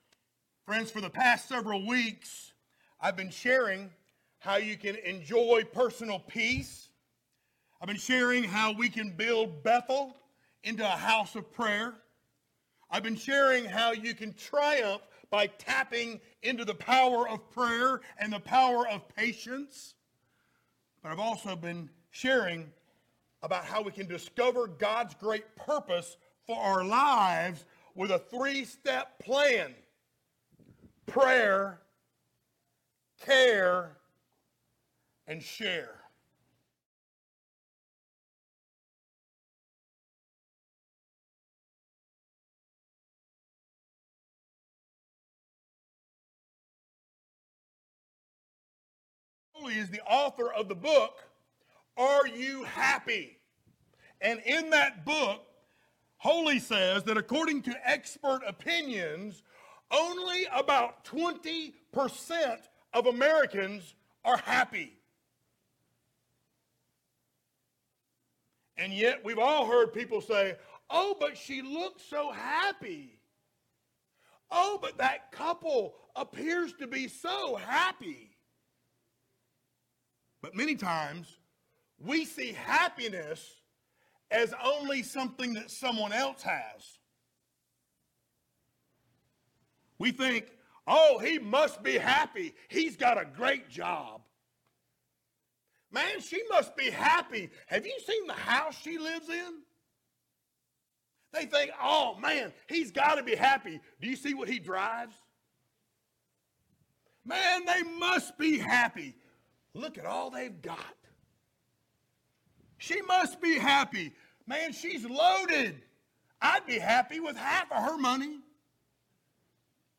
Psalms 16:1-11 Service Type: Sunday Morning Download Files Notes Topics